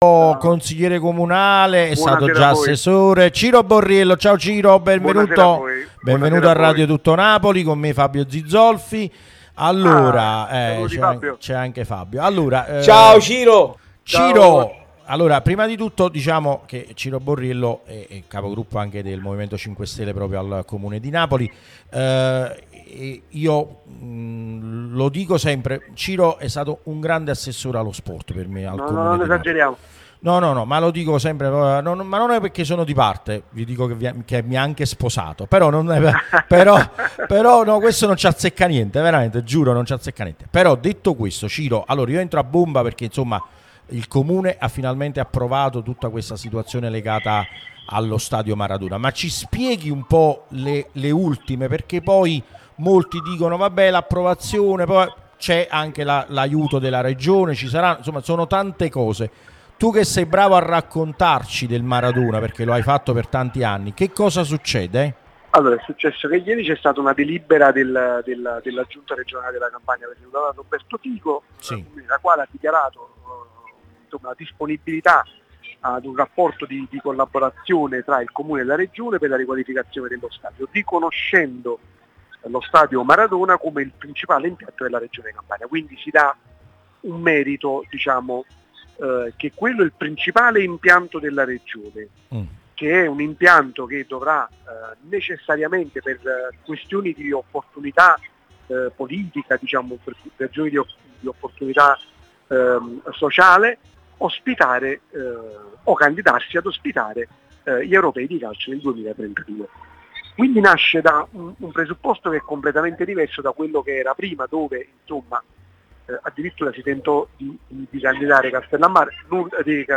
Il Consigliere di Napoli Ciro Borriello ed ex Assessore allo Sport del Comune di Napoli, è intervenuto su Radio Tutto Napoli , prima radio tematica sul Napoli, che puoi ascoltare/vedere qui sul sito , in auto col DAB Campania o sulle app gratuite ( scarica qui per Iphone o qui per Android ).